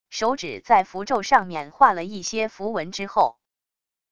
手指在符咒上面画了一些符文之后wav音频